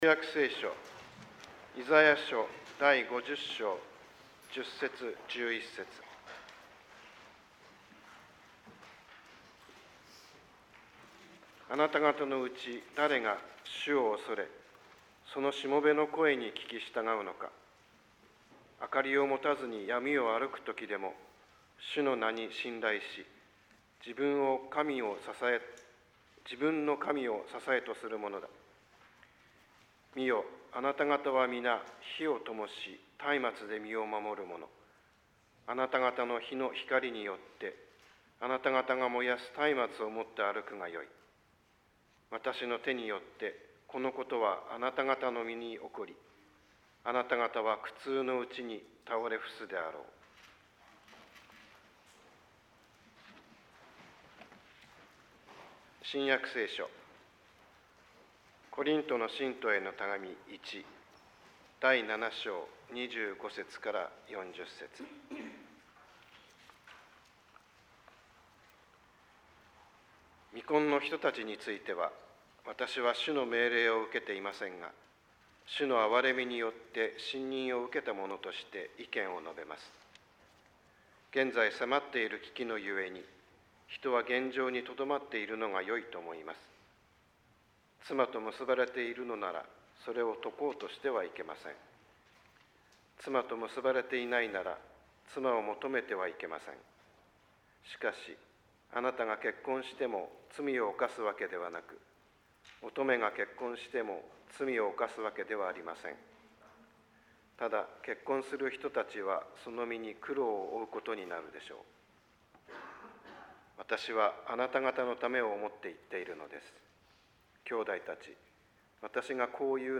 説教「ひたすら主に仕える」